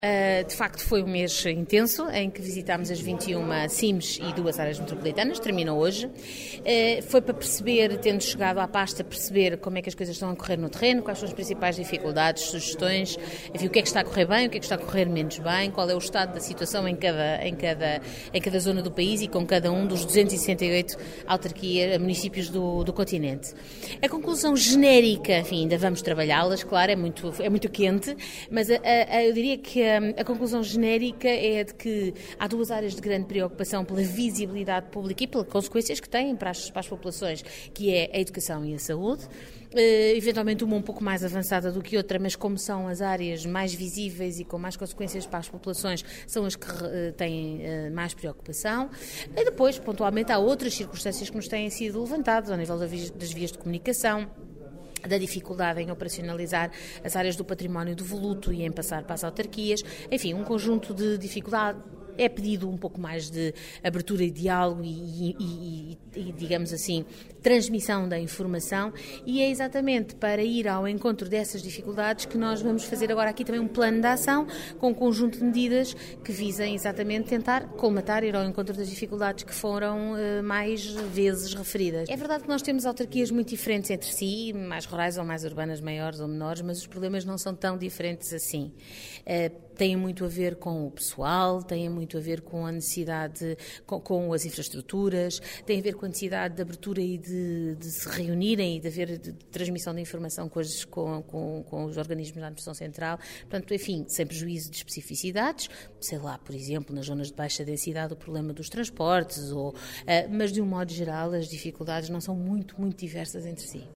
Alexandra Leitão falava à agência Lusa à margem do último encontro com autarcas, na sede da Área Metropolitana de Lisboa (AML), para fazer um balanço e levantamento de necessidades no âmbito do processo de descentralização.